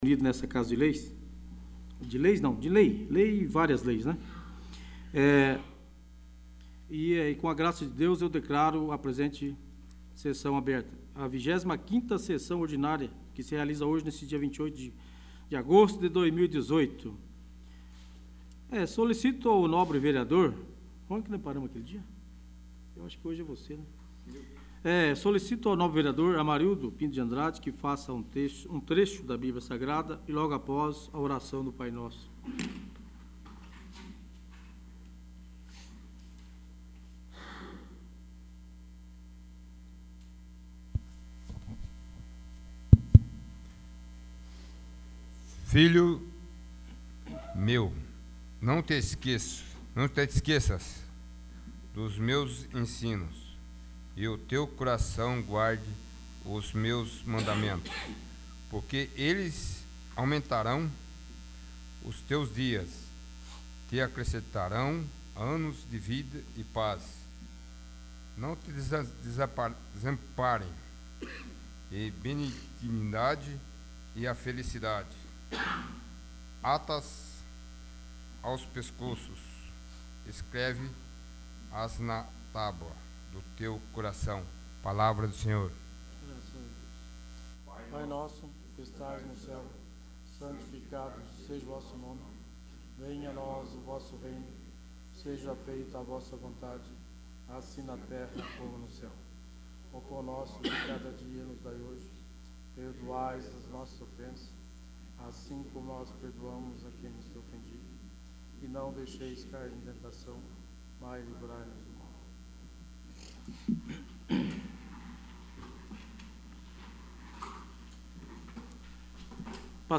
25º. Sessão Ordinária